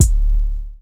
JJKicks (4).wav